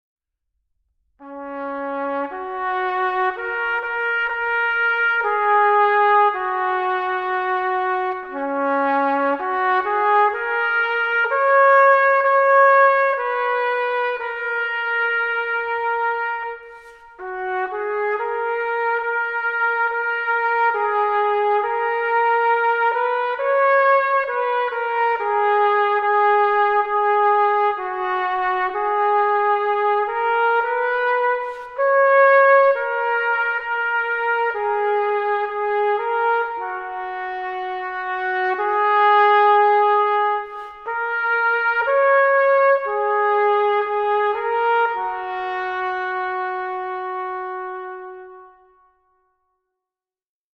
Jobporträt: Trompeter im Musikkorps
Ein Bundeswehrsoldat spielt Trompete
Es ist Bestandteil einer Beisetzung  mit militärischen Ehren und jeder militärischen Trauerfeier.